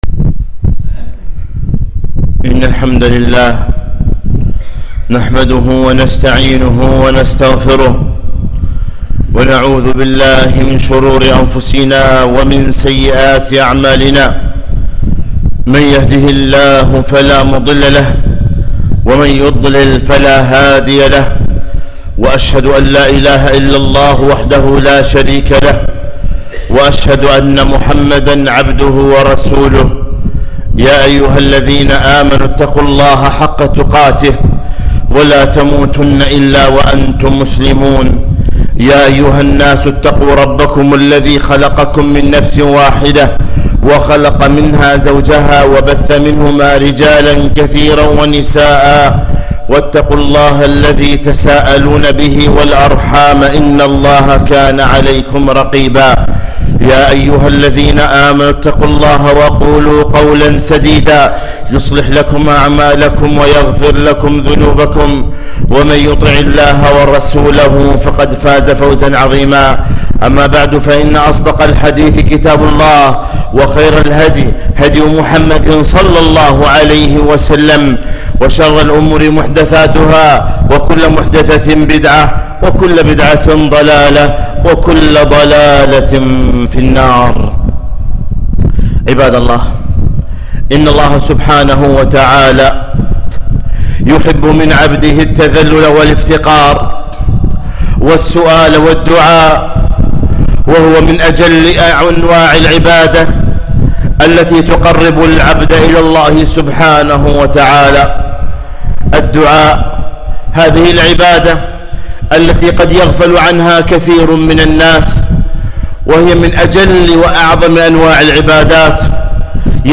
خطبة - ( أدعوا ربكم تضرعا وخفية)